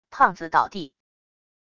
胖子倒地wav音频